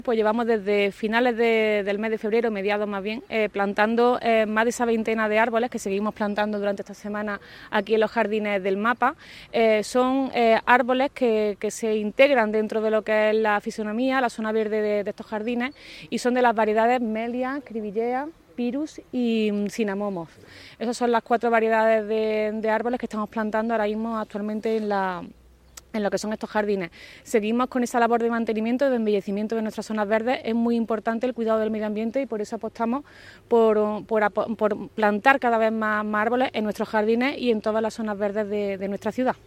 El alcalde de Antequera, Manolo Barón, y la teniente de alcalde delegada de Obras y Mantenimiento, Teresa Molina, informan de una nueva campaña de plantación de árboles que efectivos municipales de Parques y Jardines están acometiendo en los Jardines del Mapa desde mediados del presente mes de febrero.
Cortes de voz